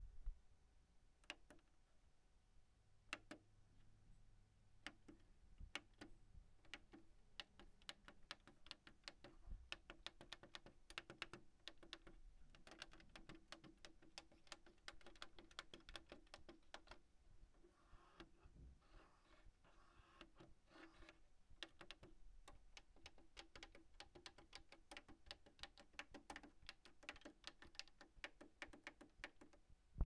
mpc button pushing.WAV